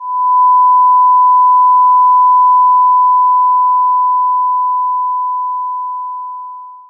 ace_earringing_medium.wav